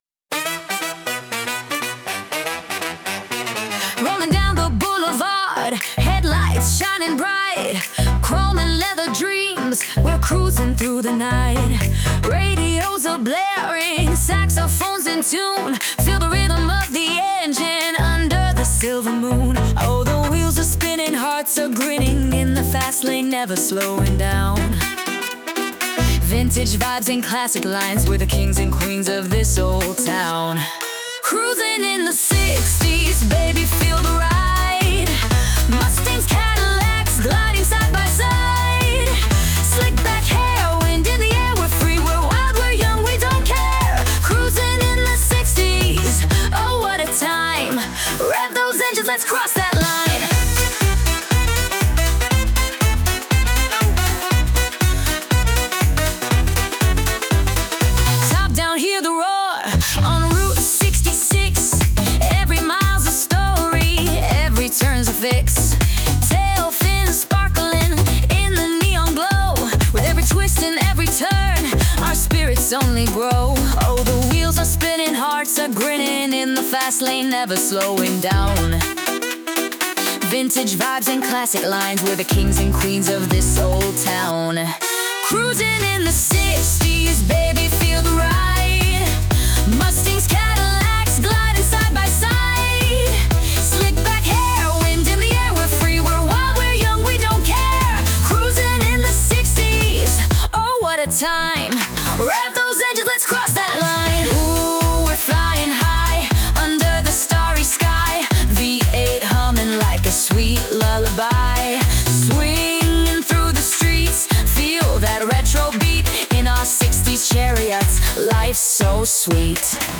cantante e produttrice italo-messicana
specializzata in electro swing, EDM e jazz-pop.